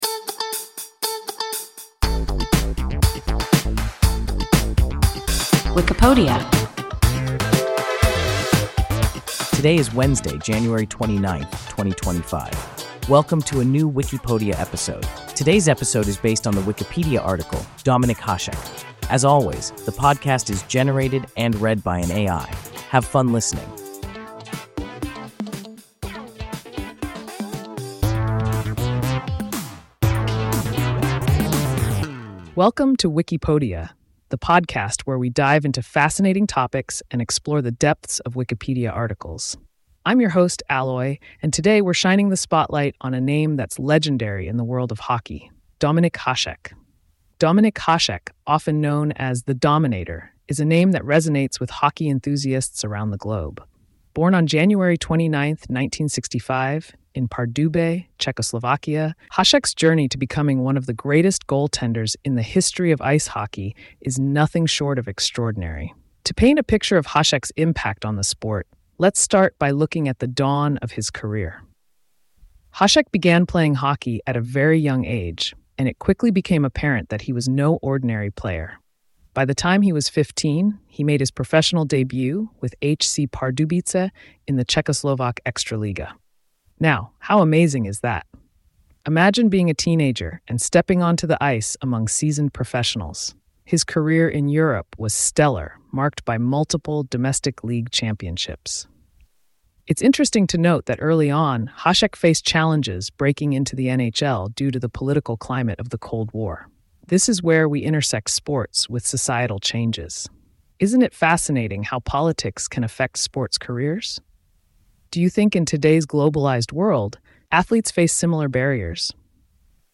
Dominik Hašek – WIKIPODIA – ein KI Podcast